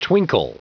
Prononciation du mot twinkle en anglais (fichier audio)
Prononciation du mot : twinkle